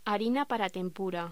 Locución: Harina para tempura